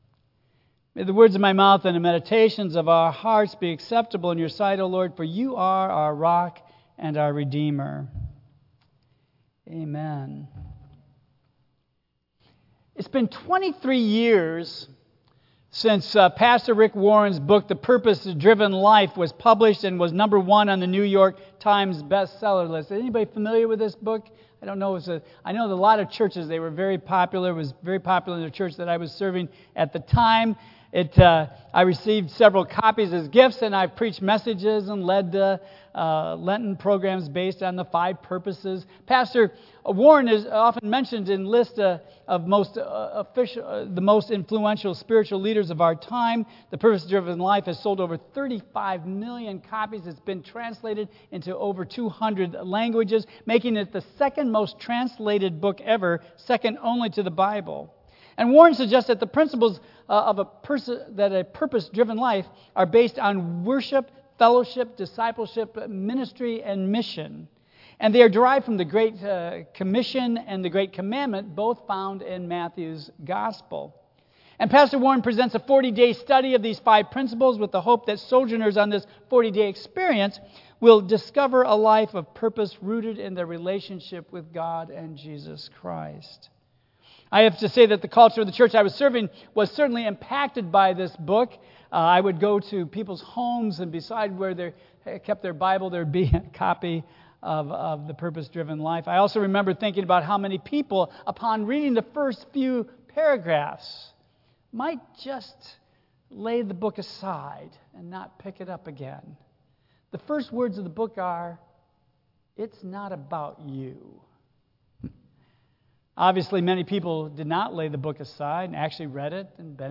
Tagged with Michigan , Sermon , Waterford Central United Methodist Church , Worship Audio (MP3) 8 MB Previous The Trinity as Architect of Spiritual Growth Next Aran's Story